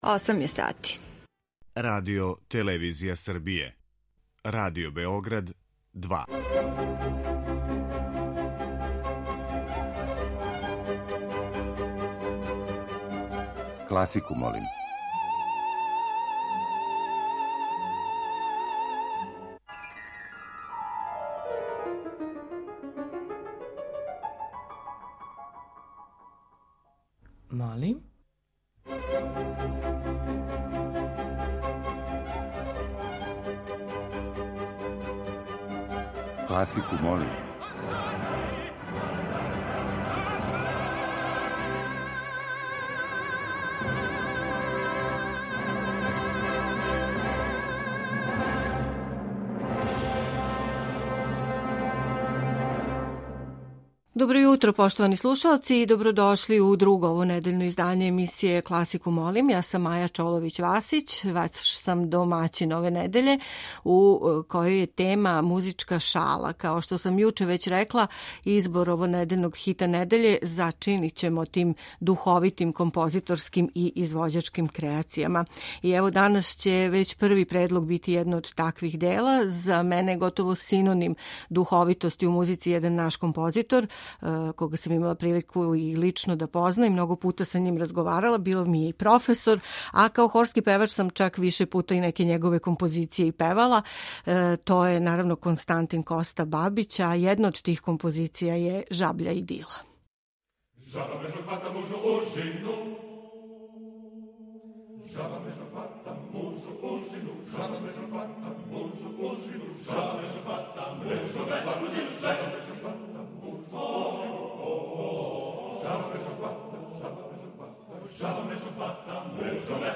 Међу њима је и неколико духовитих и шаљивих примера композиторске и извођачке креације, као део овонедељне теме, а слушаћете и четири снимка са концерата одржаних у оквиру 55. фестивала „Мокрањчеви дани".